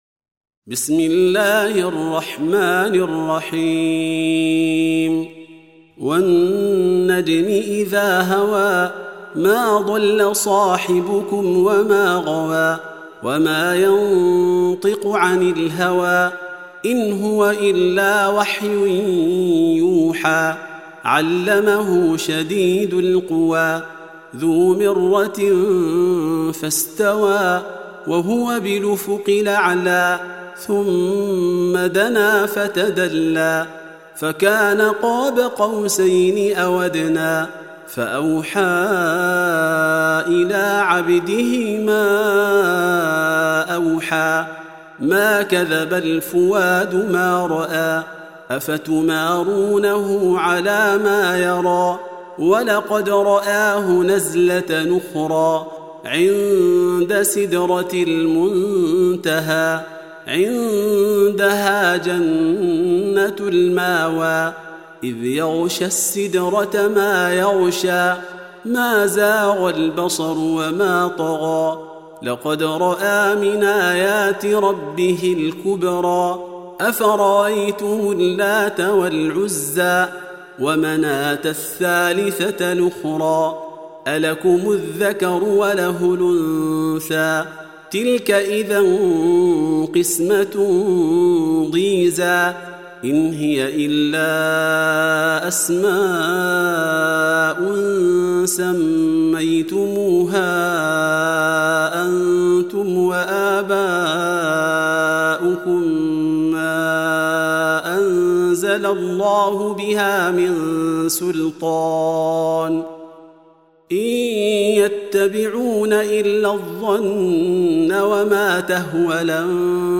Surah Repeating تكرار السورة Download Surah حمّل السورة Reciting Murattalah Audio for 53. Surah An-Najm سورة النجم N.B *Surah Includes Al-Basmalah Reciters Sequents تتابع التلاوات Reciters Repeats تكرار التلاوات